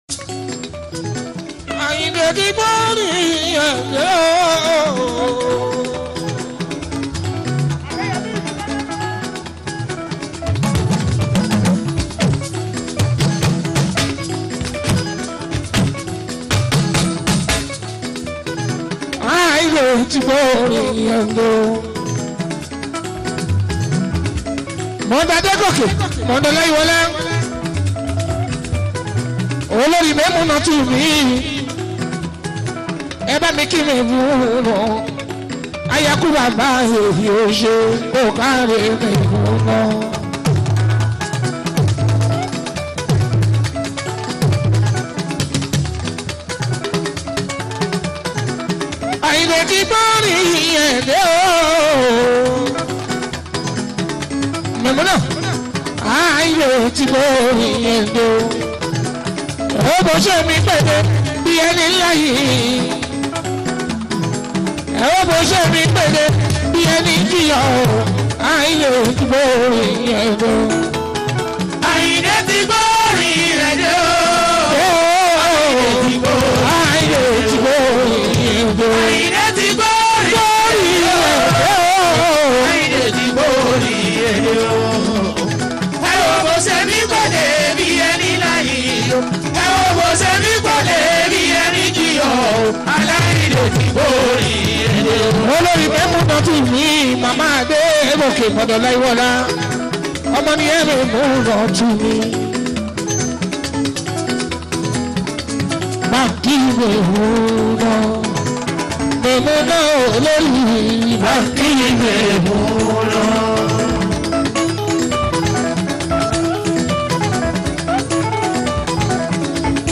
Yoruba Fuji music